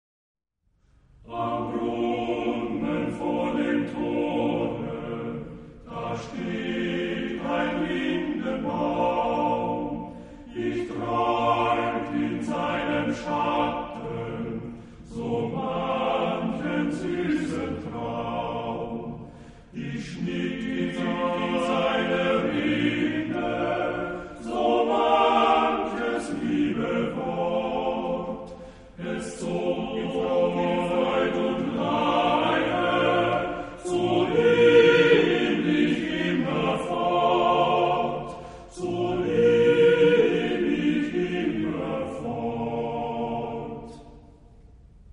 TBB (3 voix égales d'hommes) ; Partition choeur seul.
Lied.